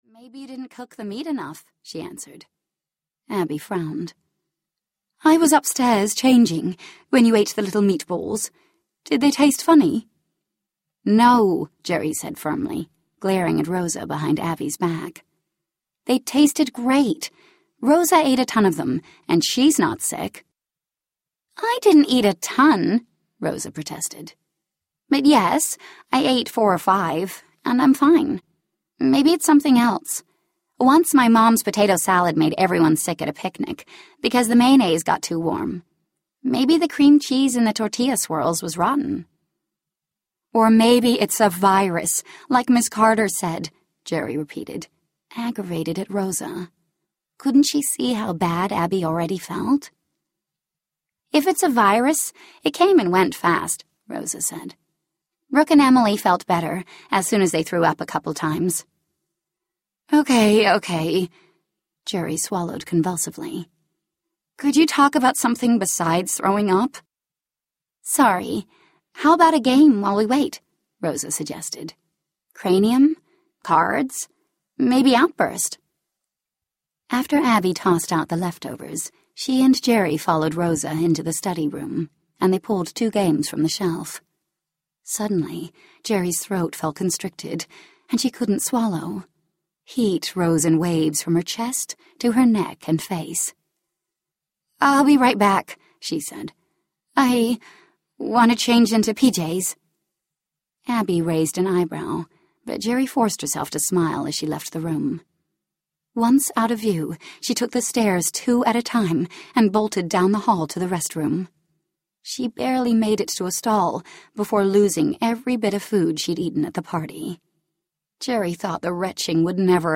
Poisoned Audiobook
Narrator
3 Hrs. – Abridged